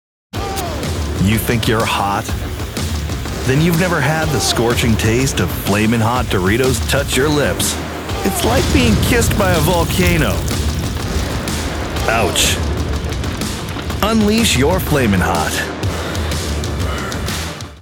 A Robust Baritone Full of Character
Snack Food Demo - English